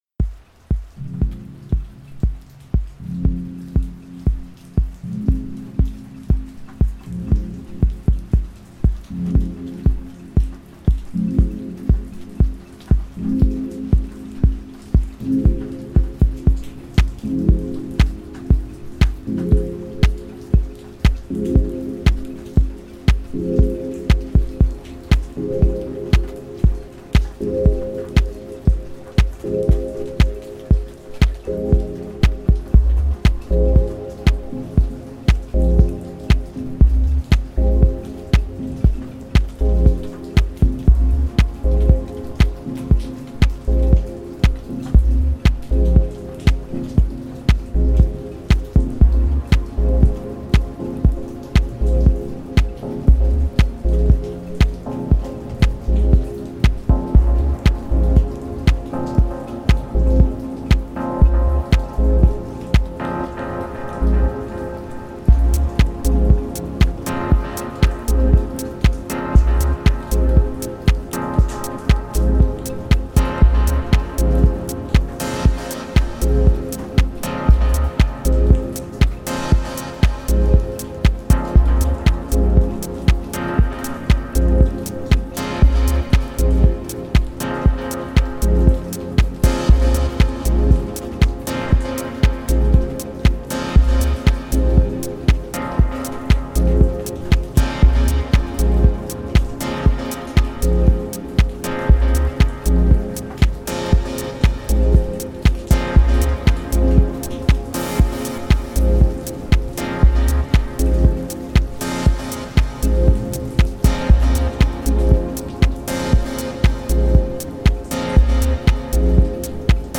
Genre: Deep House/Dub Techno.